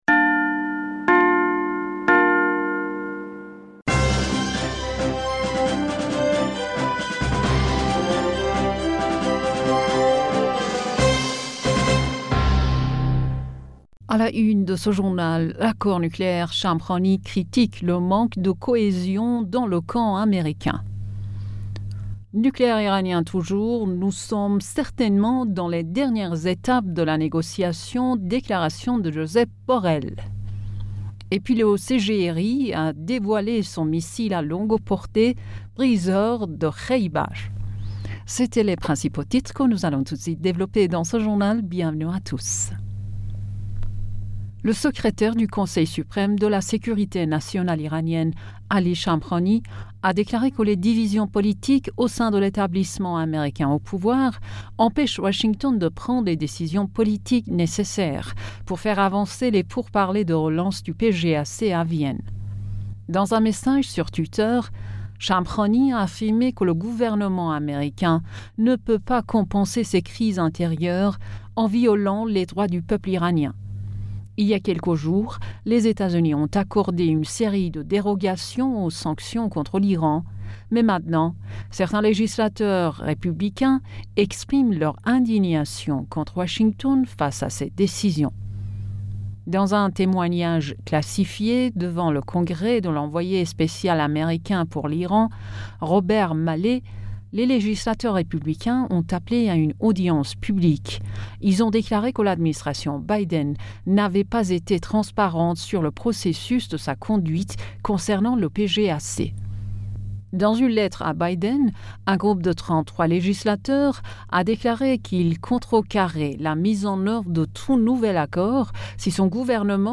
Bulletin d'information Du 09 Fevrier 2022